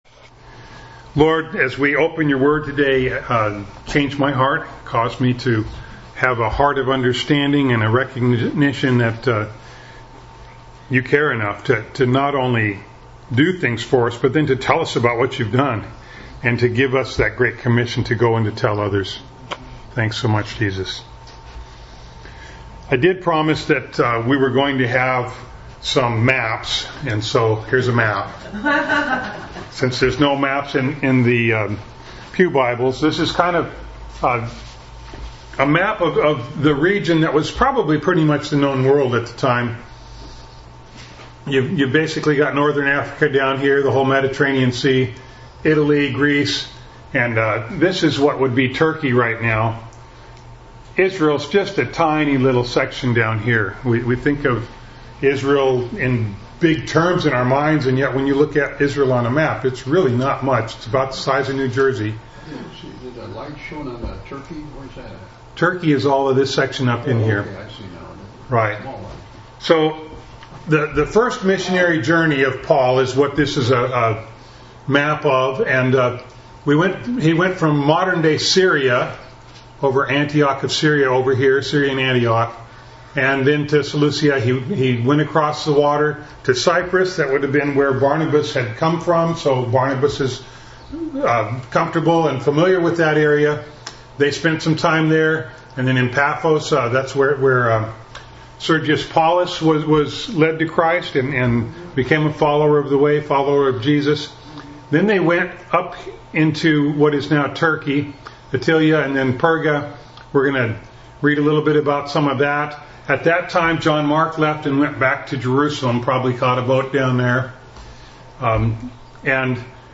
Passage: Acts 13:13-52 Service Type: Sunday Morning